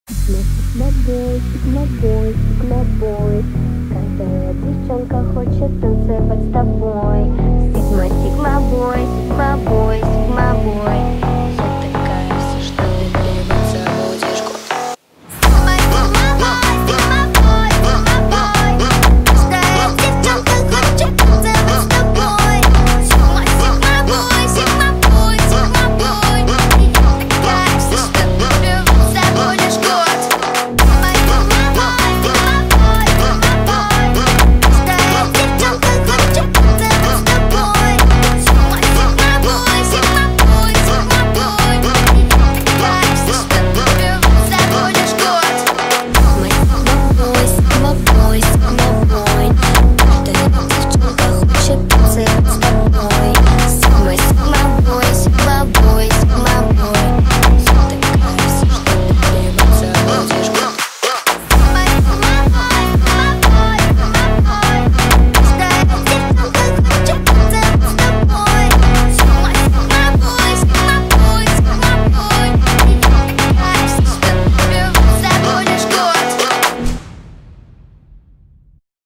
Brazilian Phonk remix